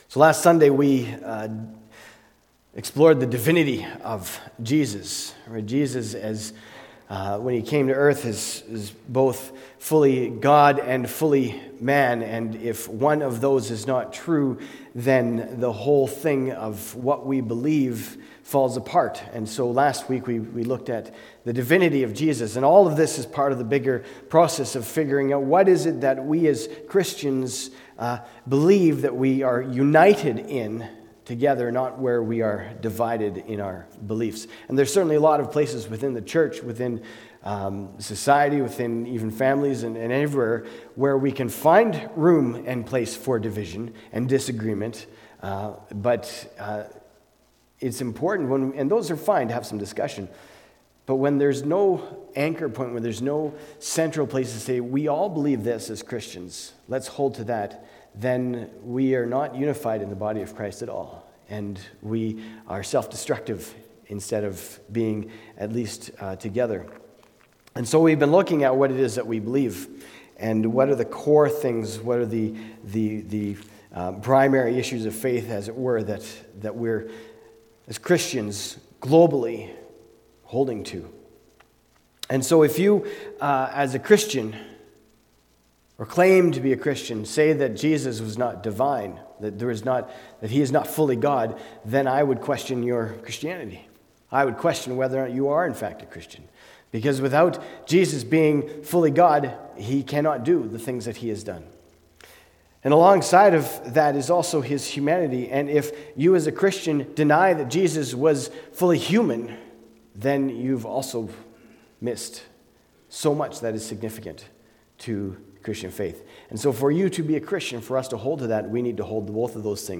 Lived, Died, Lives Again – Argyle Road Baptist Church